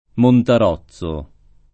montarozzo [ montar 0ZZ o ]